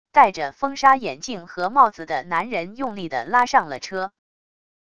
带着风沙眼镜和帽子的男人用力地拉上了车wav音频